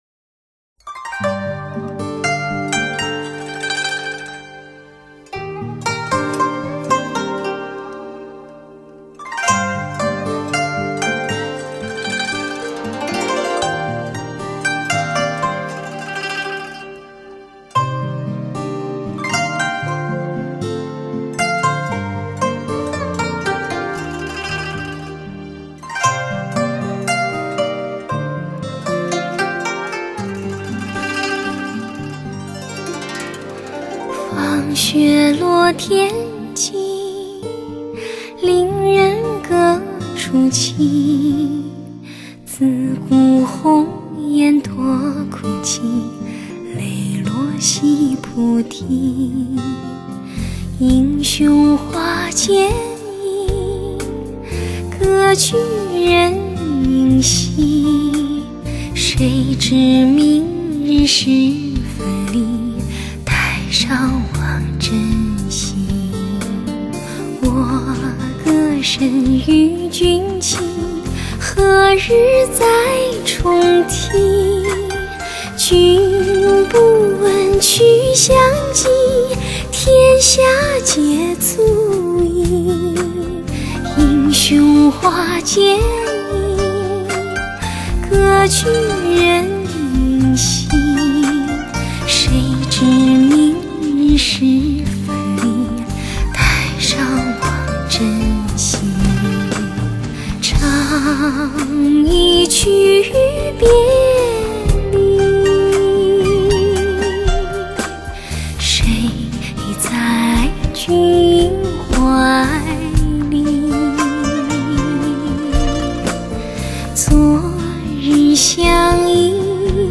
动人的旋律，倾情的演绎，